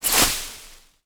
Light  Grass footsteps 4.wav